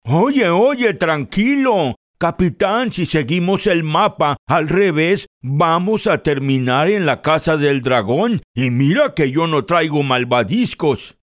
Character, Cartoon and Animation Voice Overs
Spanish (Mexican)
Adult (30-50) | Older Sound (50+)
0302Personaje_Abuelo.mp3